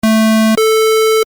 Square（矩形（くけい）波）だ。
square.mp3